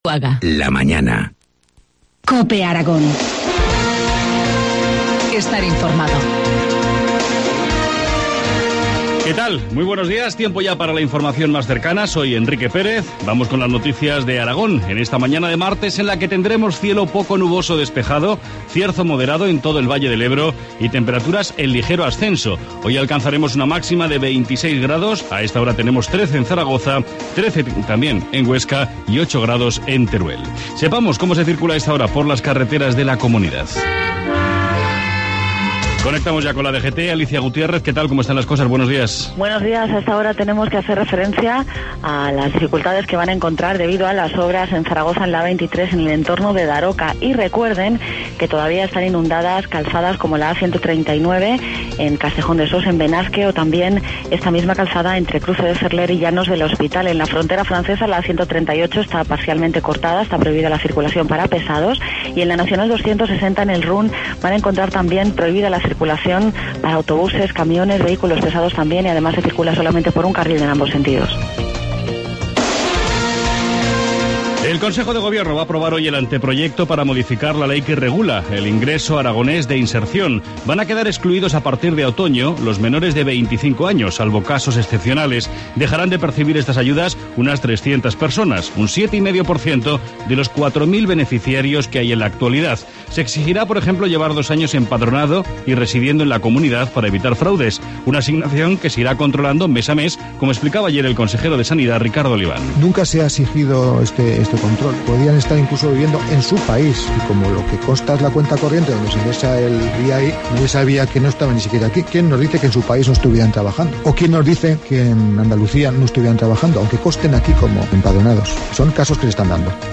Informativo matinal, 25 junio, 7,25 horas